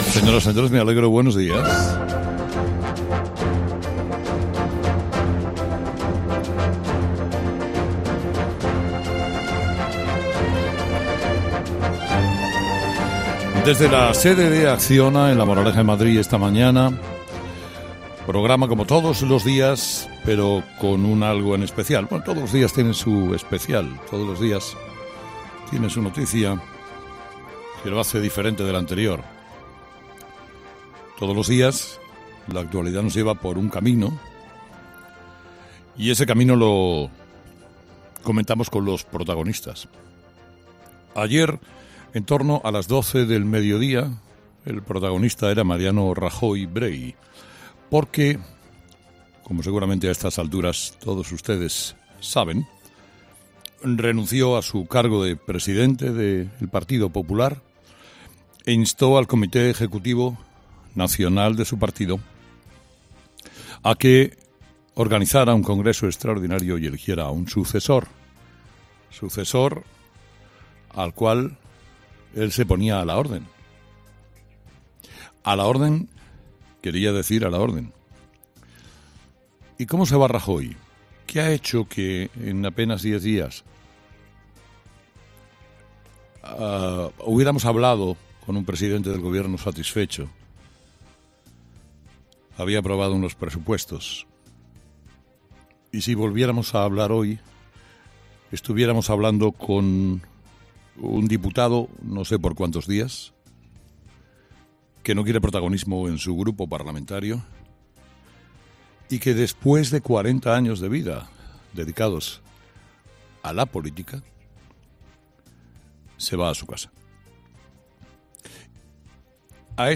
Monólogo de las 8 de Herrera